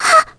Mirianne-Vox_Damage_01_kr.wav